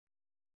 ♪ kaṭṭigegāṛa